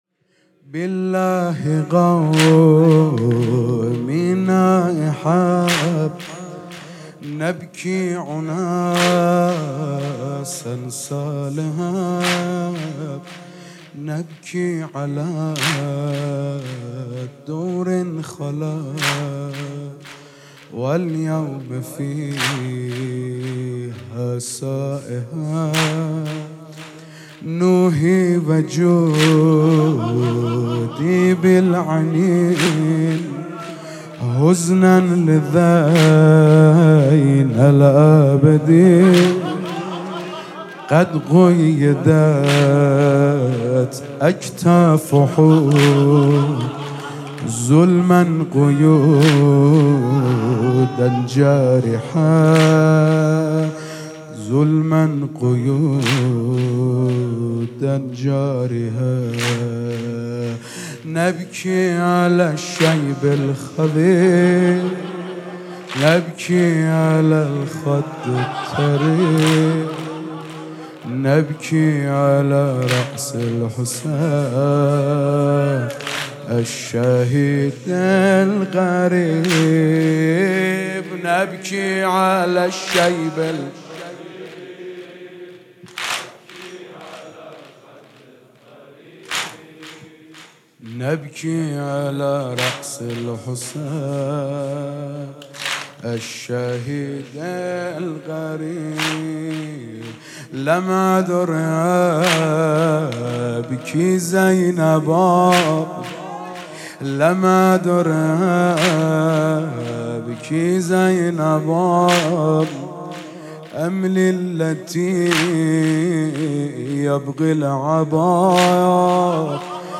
شب اول محرم 95/هیت عبدالله بن الحسن(ع)